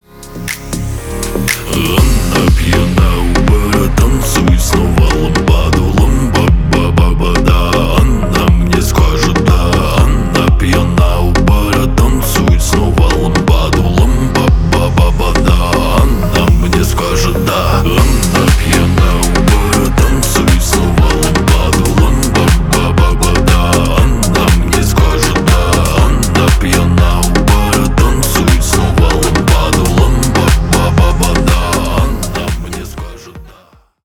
Поп Музыка # Танцевальные